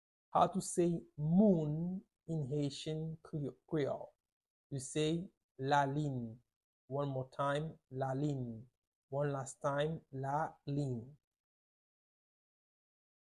Pronunciation:
12.How-to-say-Moon-in-Haitian-Creole-–-lalin-with-Pronunciation.mp3